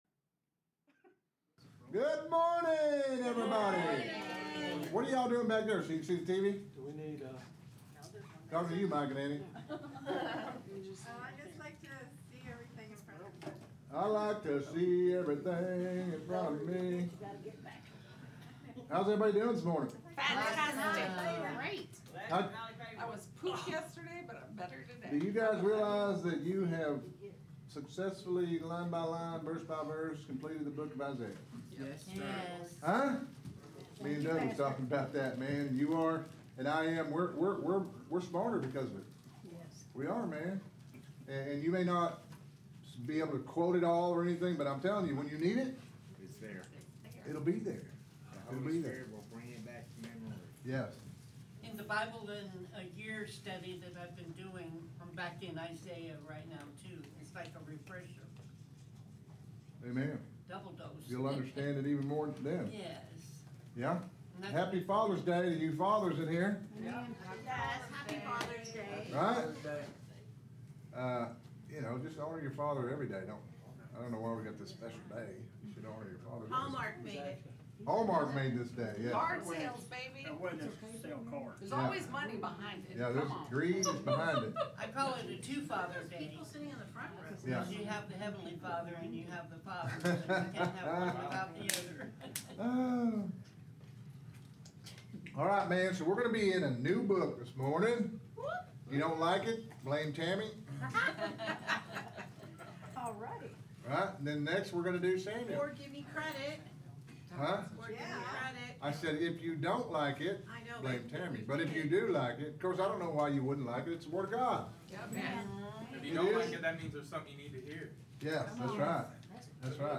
Interactive Bible Study